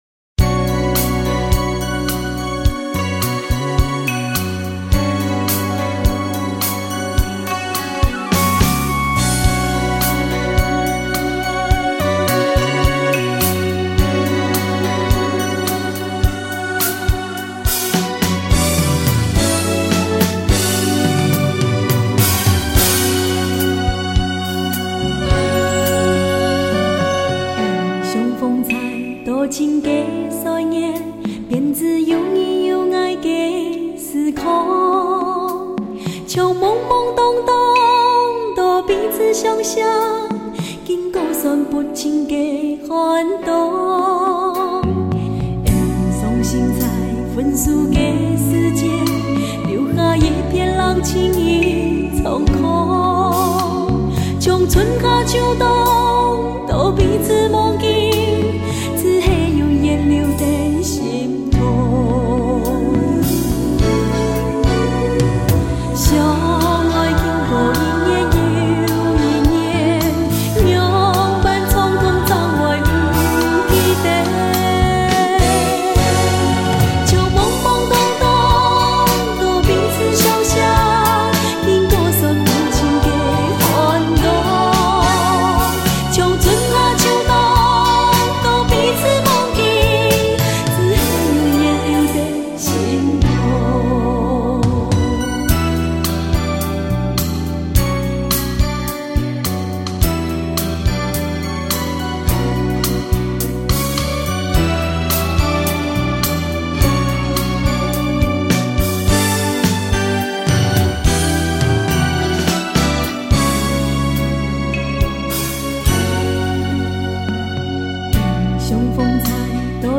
她不僅歌聲優美，人也十分美麗可愛。她的歌路以情歌為主，詮釋起情歌來可說是絲絲入扣。